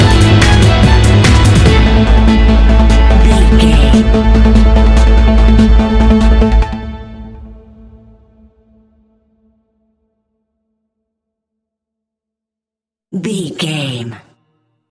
Ionian/Major
energetic
uplifting
hypnotic
drum machine
synthesiser
violin
piano
acid house
synth leads
synth bass